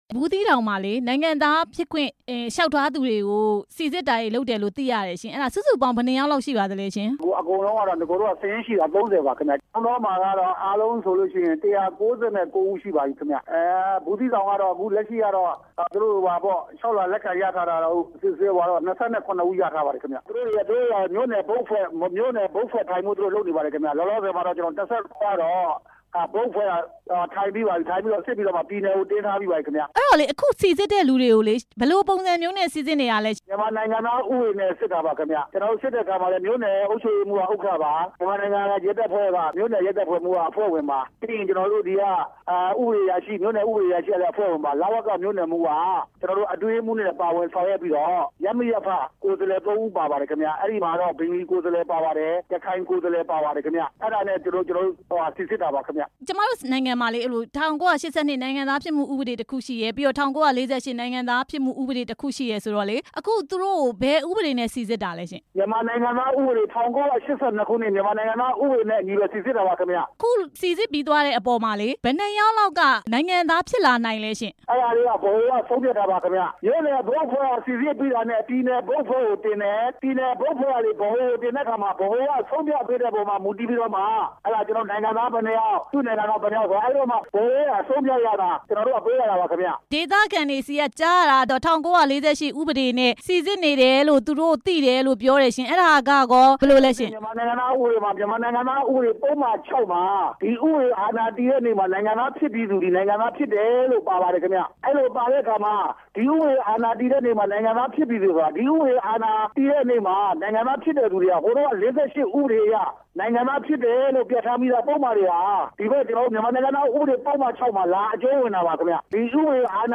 ရခိုင်ပြည်နယ် လဝက ညွန်ကြားရေးမှူးချုပ် ဦးခင်စိုးနဲ့ မေးမြန်းချက်